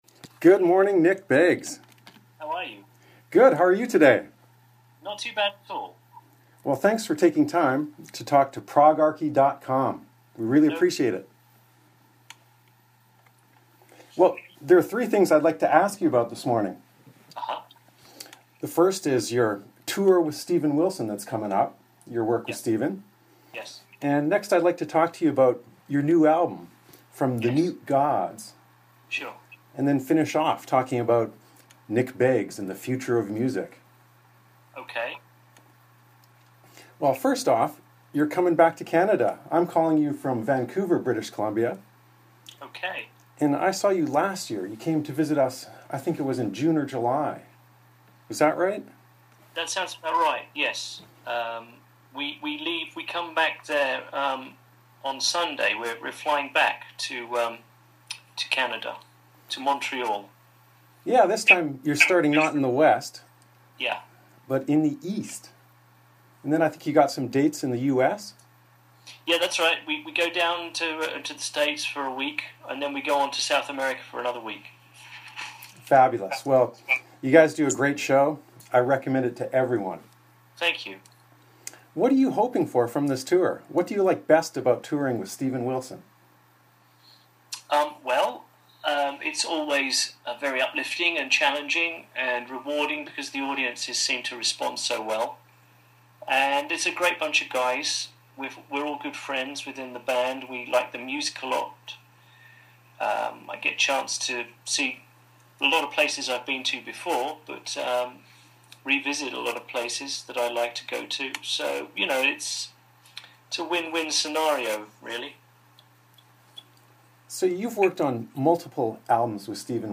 01-nick-beggs_-interview-with-progarchy.mp3